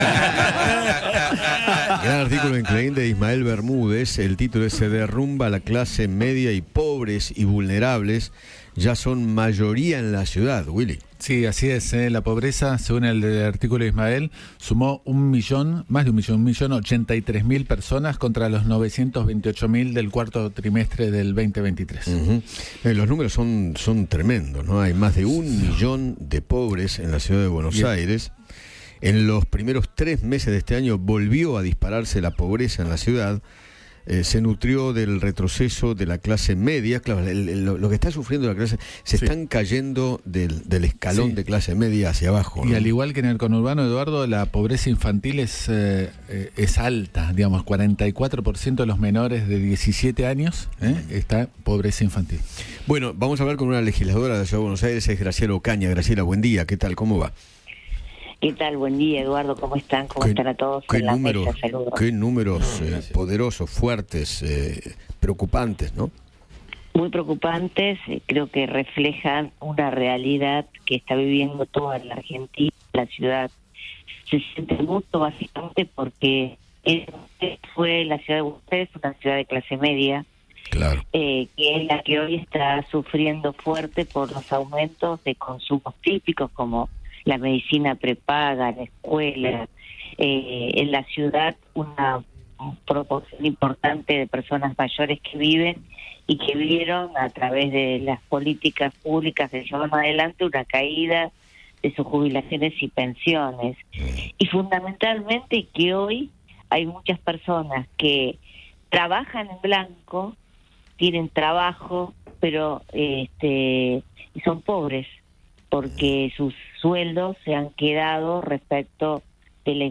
Graciela Ocaña, legisladora porteña, conversó con Eduardo Feinmann sobre los datos de pobreza arrojados por la Dirección de Estadística y Censos de la Ciudad de Buenos Aires.